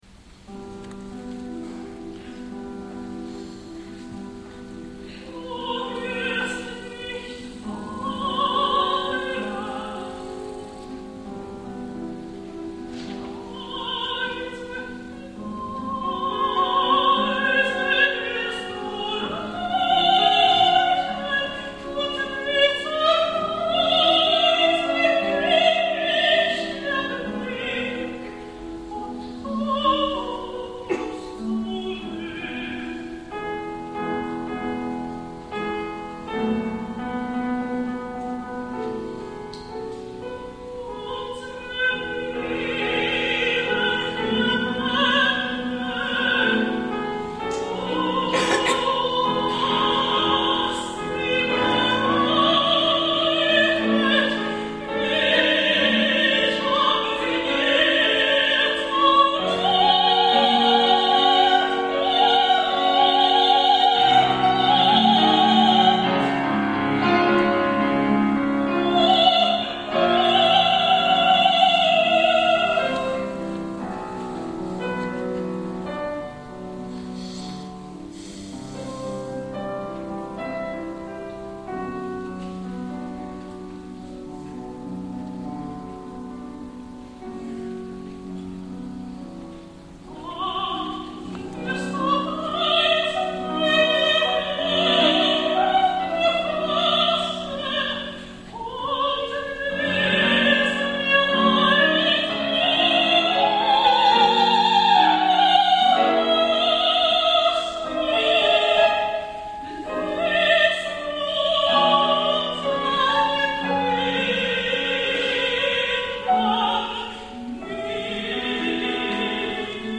Ens havien anunciat la Teresa Berganza, però un cop més i fidel a la seva llegenda negra, ha cancel·lat, però potser millor i deixeu-me ser cruel, doncs la substitució ha anat a càrrec de la Violeta Urmana, la soprano guanyadora del primer premi femení (aleshores mezzosoprano) del any 1992 i avui un dels estels més rutilants del panorama actual.
Us deixo algunes perles de les que ha cantat avui però provinents d’un altre recital en directe amb acompanyament al piano